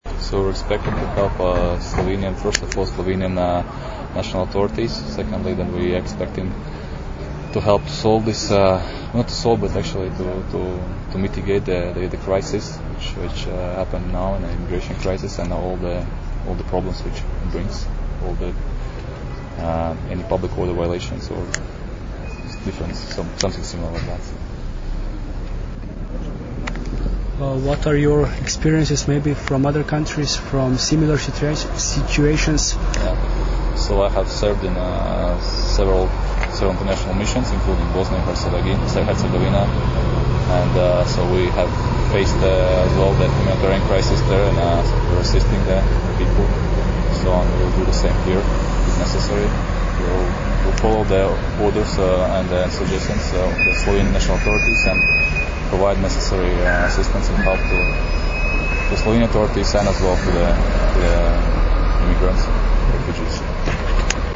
Zvočni zapis izjave